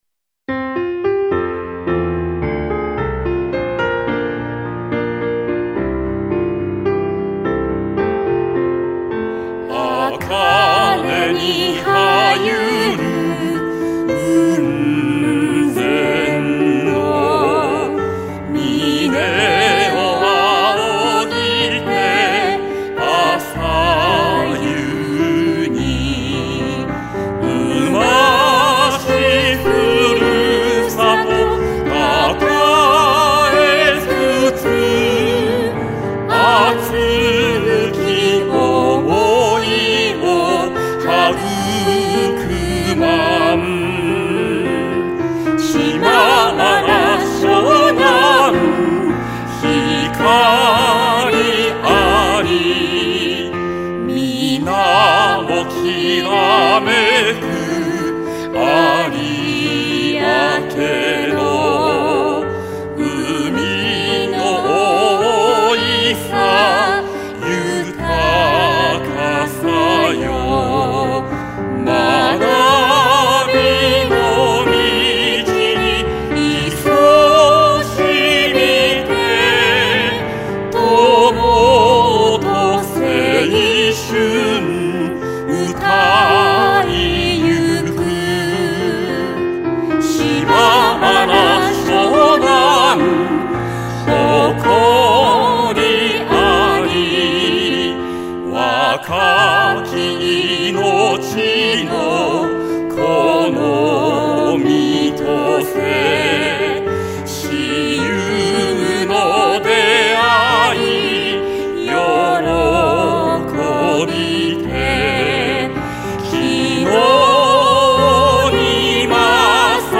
校歌
作詞：平田　徳男　　　　作曲：三上　次郎
島原翔南高校　校歌（歌入り）［MP3ファイル／2MB］